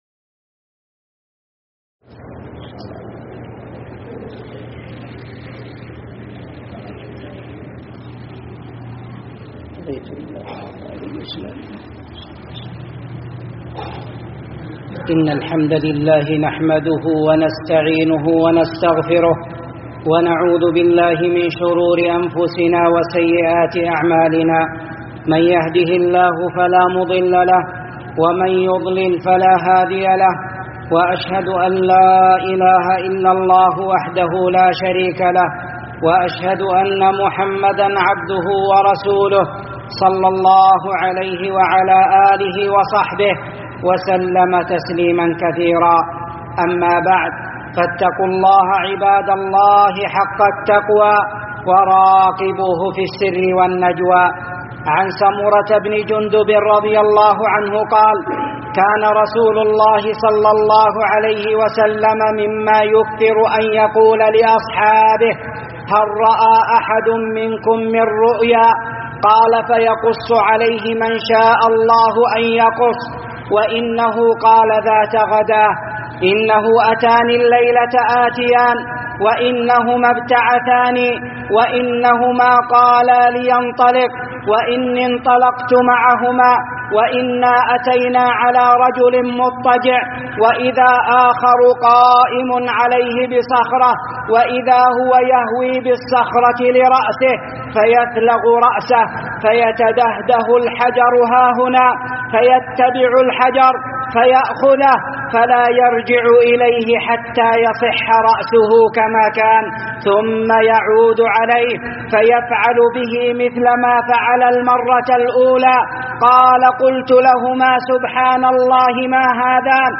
26 جويلية 2024 م 4.1M 00:08:51 مواعظ ورقائق 105 21 باقي خطب الشيخ كل الخطب سماع الخطبة تحميل الخطبة شارك